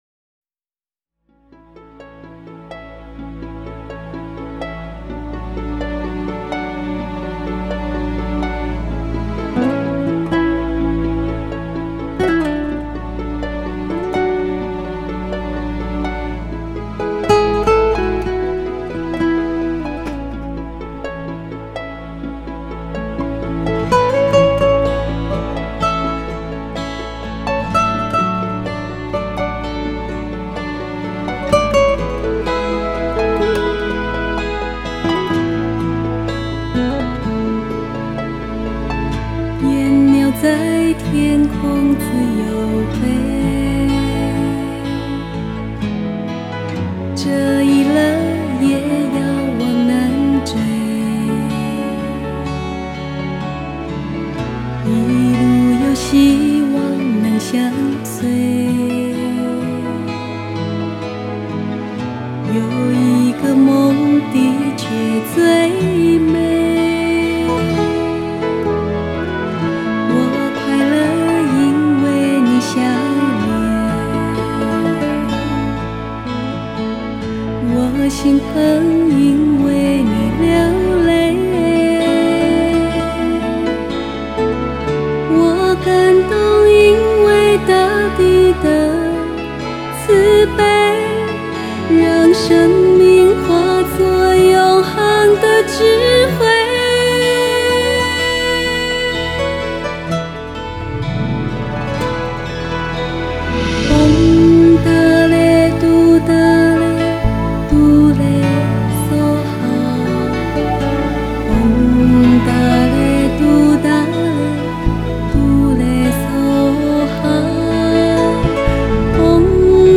加上慢板的旋律在自由风格里，滴进了一点点忧郁，呈现一种迷离人世的风情